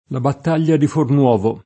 Fornovo [forn0vo] top. e cogn.